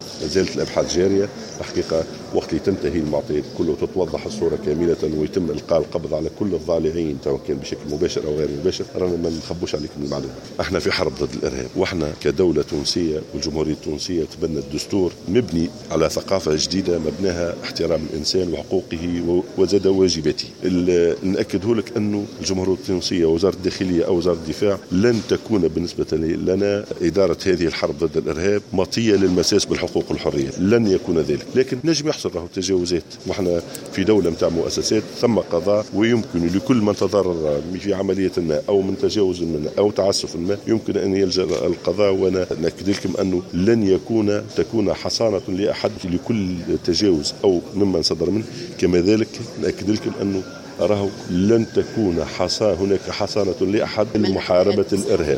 وأضاف في تصريحات صحفية على هامش انعقاد مؤتمر قادة الشرطة والأمن العرب بمقر مجلس وزراء الداخلية العرب بالعاصمة تونس، انه عندما تستكمل المعطيات ويتم القبض على المورطين بطريقة مباشرة وغير مباشرة فإنه سيتم الإعلان عن مستجدات هذا الملف في أوانه.